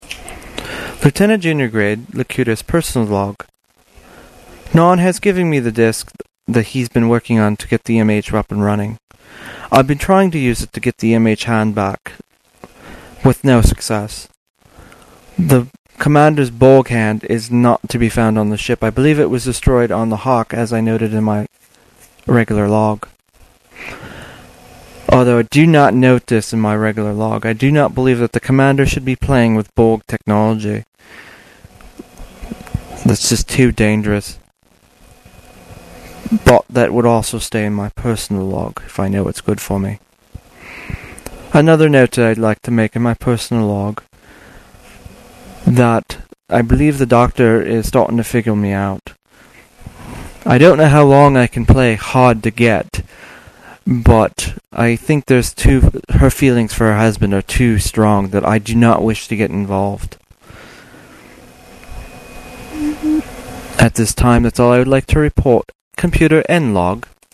Here you will here some of the voice logs I did for the QOB.